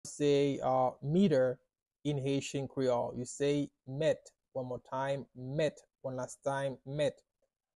“Meters” in Haitian Creole – “Mèt” pronunciation by a native Haitian Creole tutor
“Mèt” Pronunciation in Haitian Creole by a native Haitian can be heard in the audio here or in the video below:
How-to-say-Meters-in-Haitian-Creole-–-Met-pronunciation-by-a-native-Haitian-Creole-tutor.mp3